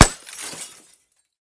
3098b9f051 Divergent / mods / JSRS Sound Mod / gamedata / sounds / material / bullet / collide / glass01hl.ogg 44 KiB (Stored with Git LFS) Raw History
glass01hl.ogg